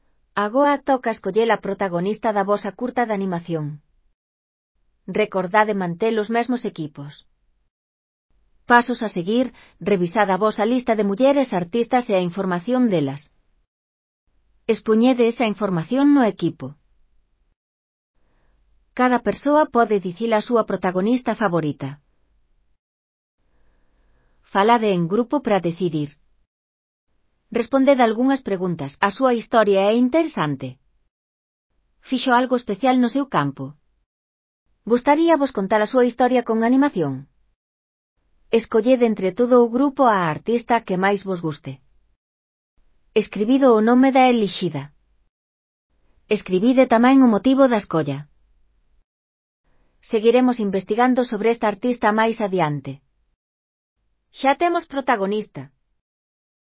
Elaboración propia (proxecto cREAgal) con apoio de IA, voz sintética xerada co modelo Celtia.